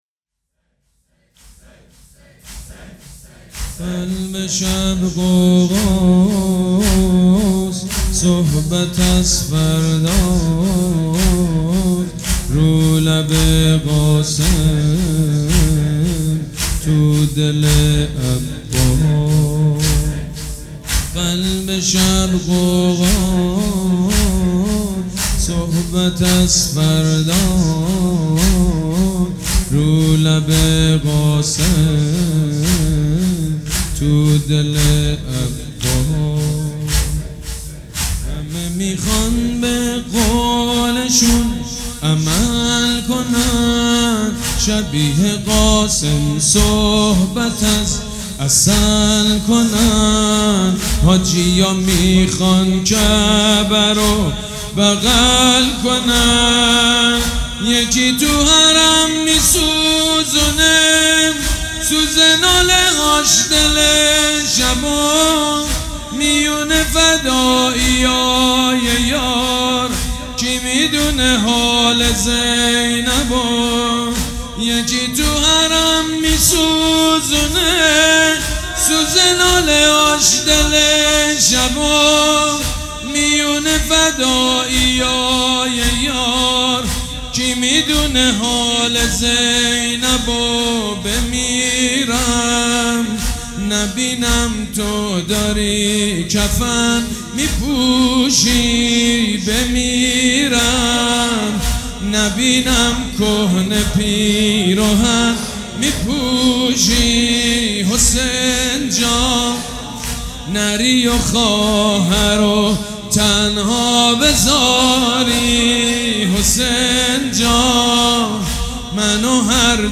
مداح
مراسم عزاداری شب عاشورا